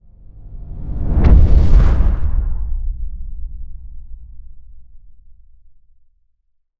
cinematic_deep_low_whoosh_impact_04.wav